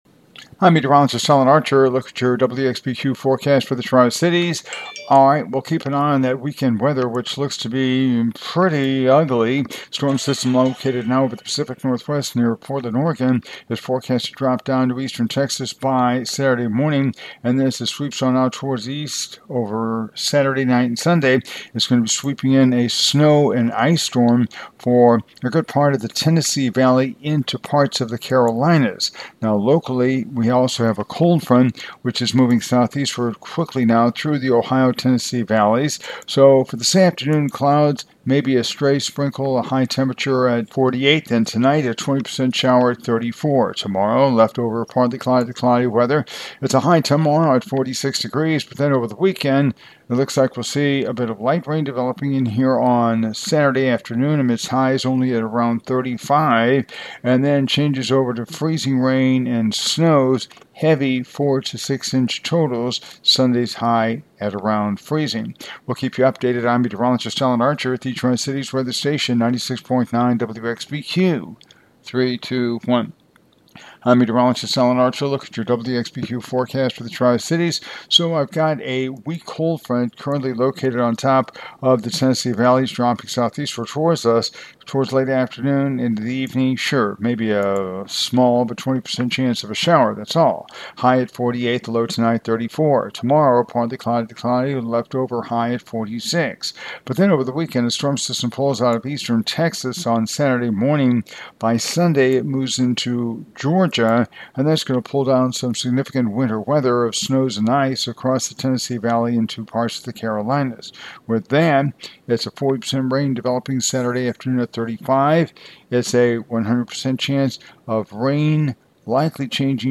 Continental Weather Corporation - Sample Weathercast
Sample weathercast for one of our 35+ year continuous clients!